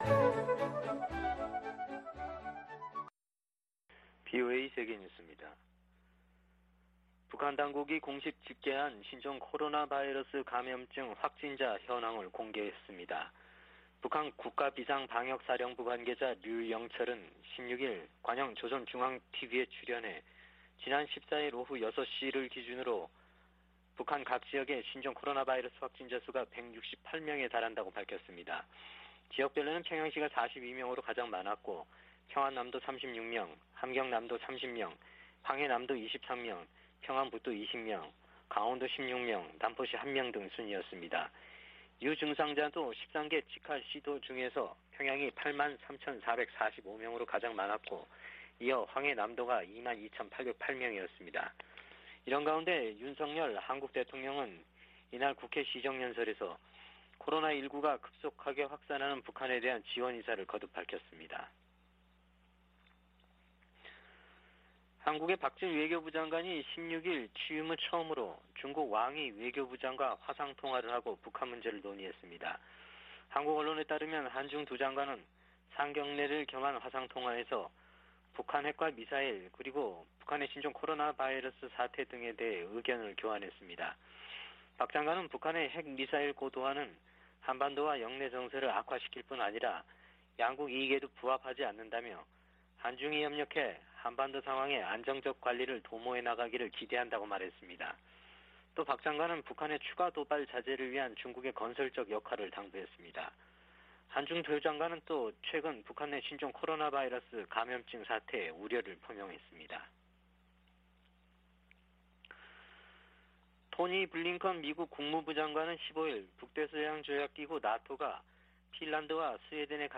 VOA 한국어 아침 뉴스 프로그램 '워싱턴 뉴스 광장' 2022년 5월 17일 방송입니다. 북한에서 신종 코로나바이러스 감염병이 폭증한 가운데, 윤석열 한국 대통령은 백신 등 방역 지원을 아끼지 않겠다고 밝혔습니다. 미 국무부는 한국 정부의 대북 백신 지원 방침에 지지 입장을 밝히고, 북한이 국제사회와 협력해 긴급 백신 접종을 실시할 것을 촉구했습니다. 미국과 아세안은 특별정상회의에서 채택한 공동 비전성명에서 한반도의 완전한 비핵화 목표를 확인했습니다.